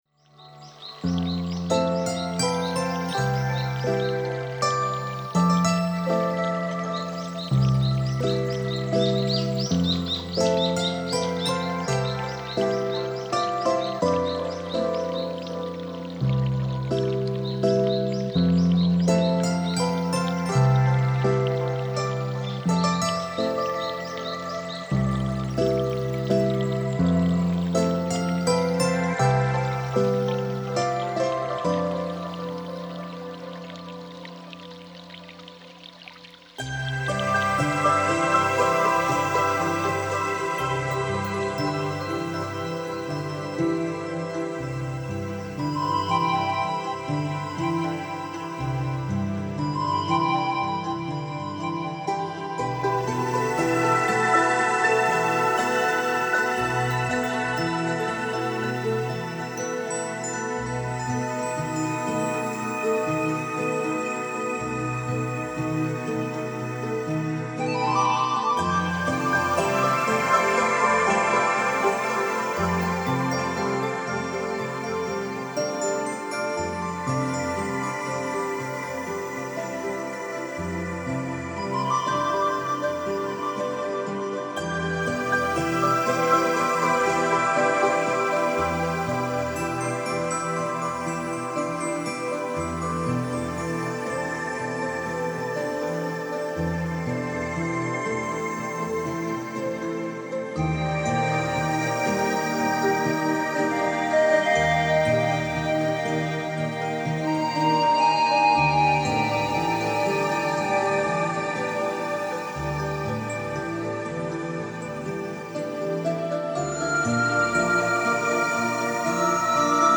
LA MUSIQUE D'AMBIANCE